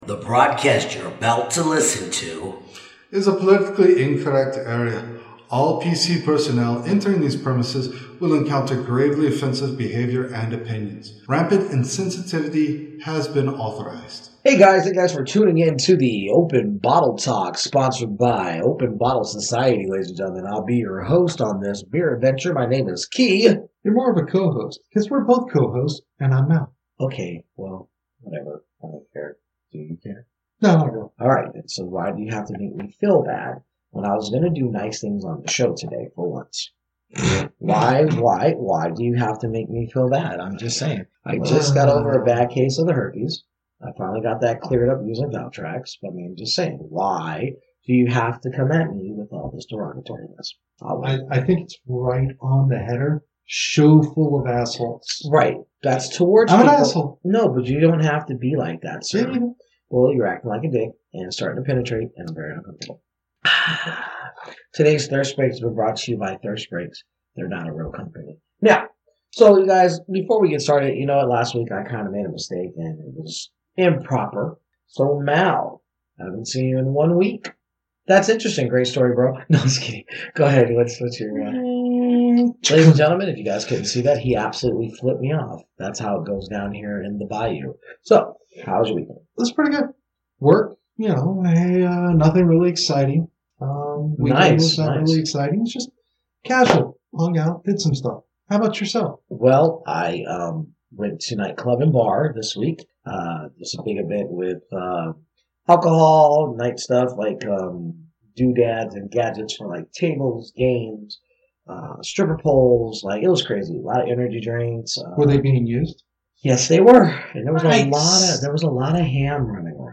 Styles: Beer Talk, Beer News, Beer, Talk Show